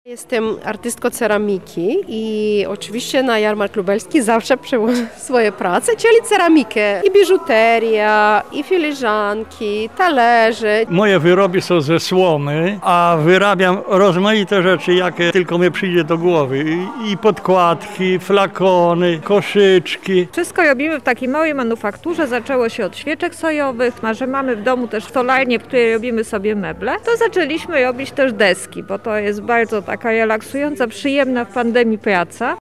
Na pierwszej odsłonie środowego Jarmarku Lubelskiego była nasza reporterka: